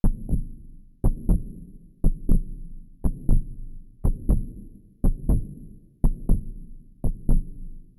SFX_Heartbeat.wav